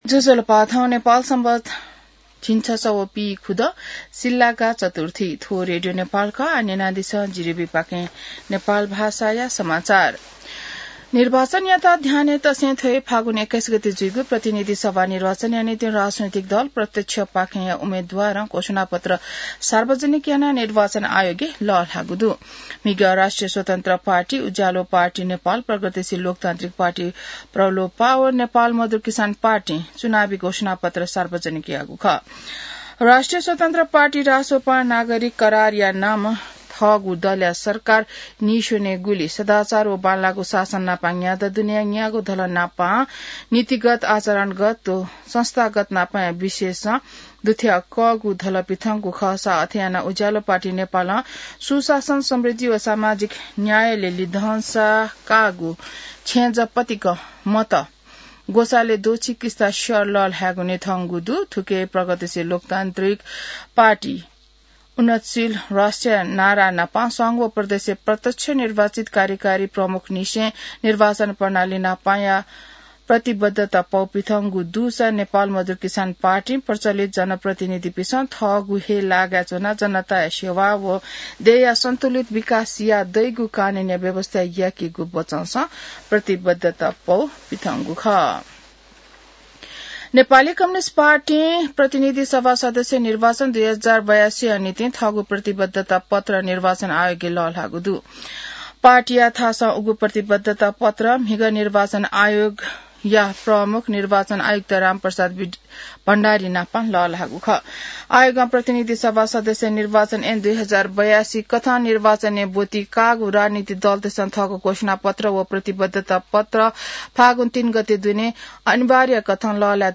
नेपाल भाषामा समाचार : ४ फागुन , २०८२